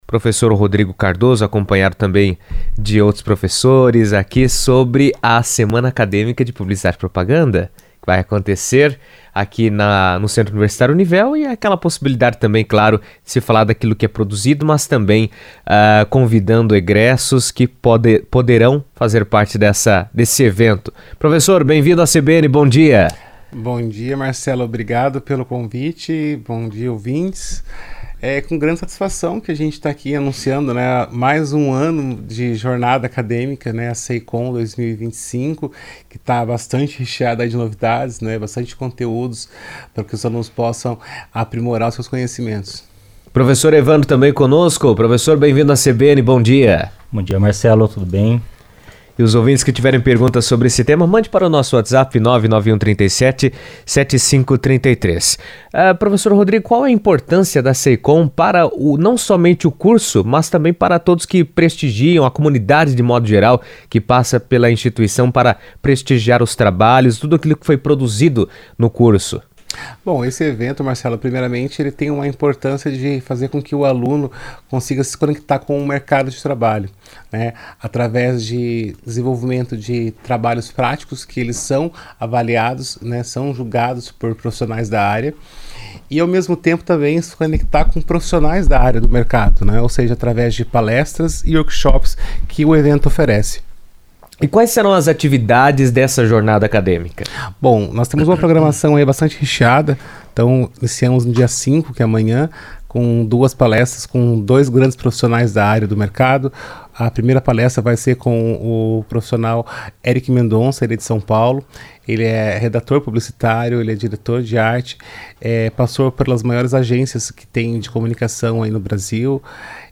A SEINCOM 2025, Semana Integrada de Comunicação da Univel, reúne acadêmicos, profissionais e convidados do setor para debater tendências, compartilhar experiências e promover o intercâmbio de conhecimento na área da comunicação. Em entrevista à CBN